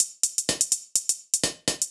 Index of /musicradar/ultimate-hihat-samples/125bpm
UHH_ElectroHatD_125-01.wav